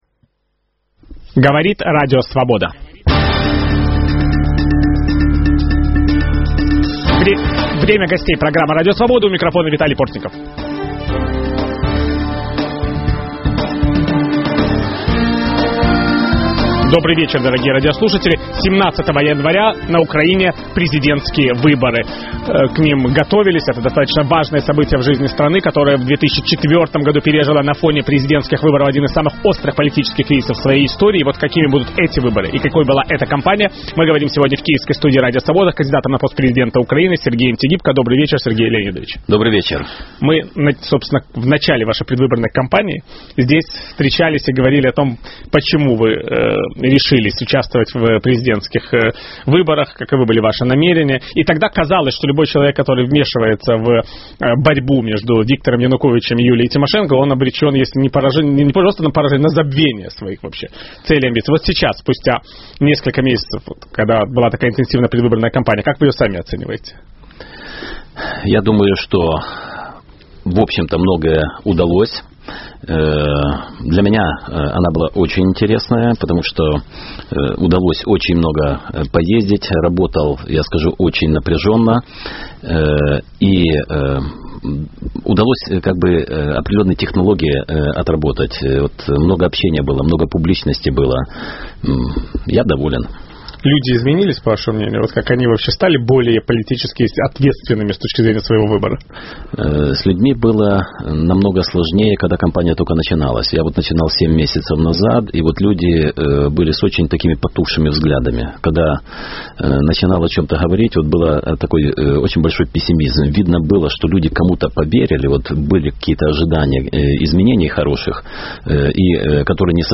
Изменится ли Украина после выборов 17 января? Виталий Портников беседует с кандидатом на пост президента Украины Сергеем Тигипко.